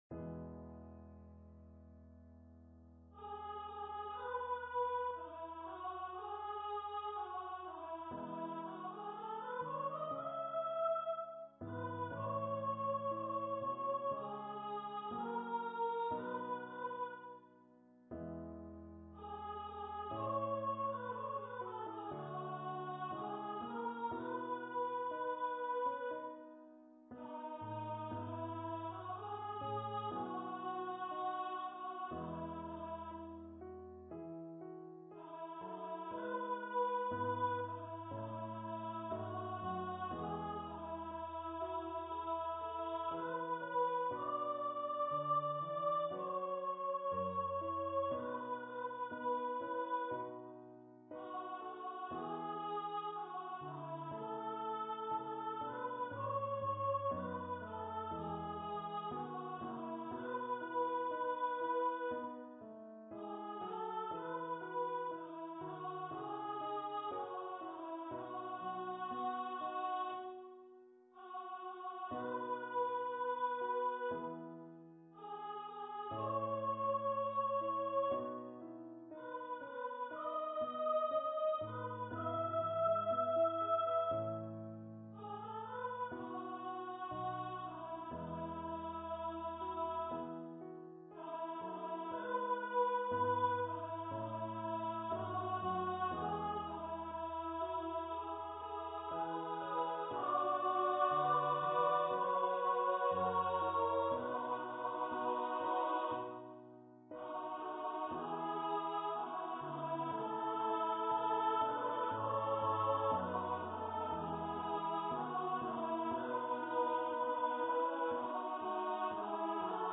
for female voice choir and piano
arranged for solo voice, SSA choir and piano.